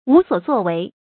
注音：ㄨˊ ㄙㄨㄛˇ ㄗㄨㄛˋ ㄨㄟˊ